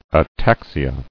[a·tax·i·a]